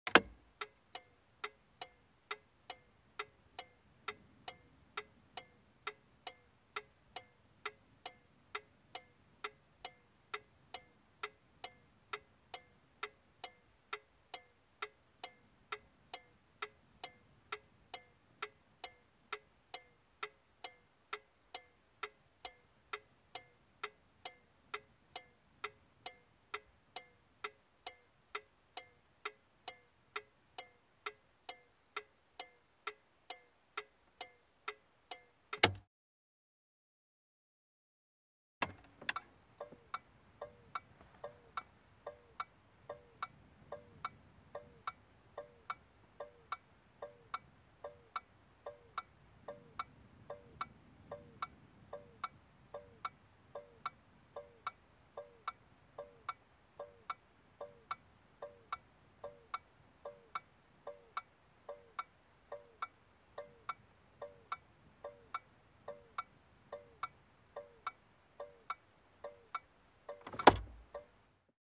Звуки поворотников
На этой странице собраны различные звуки поворотников автомобилей: от классических щелчков реле до современных электронных сигналов.